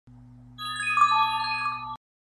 Ambient sound effects
hechizo_Hechizo_1.mp3